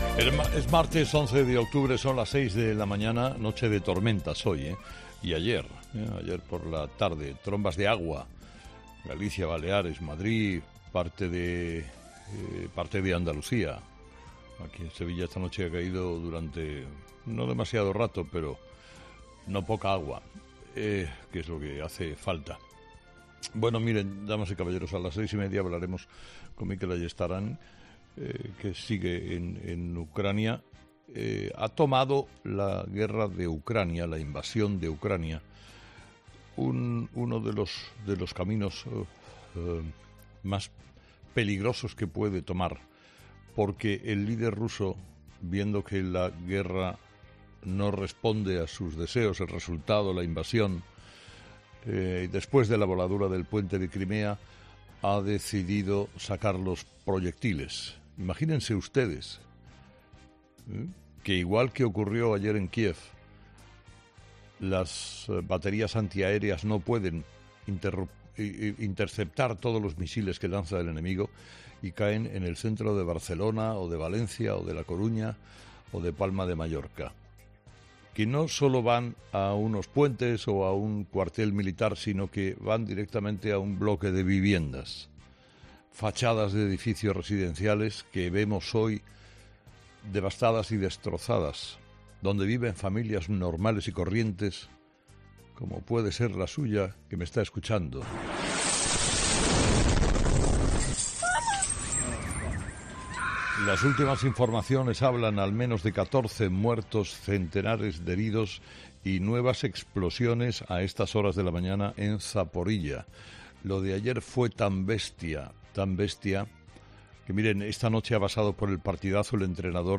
Carlos Herrera, director y presentador de 'Herrera en COPE', ha comenzado el programa de este lunes analizando las principales claves de la jornada, que pasan, entre otros asuntos, por la reunión entre Sánchez y Feijóo.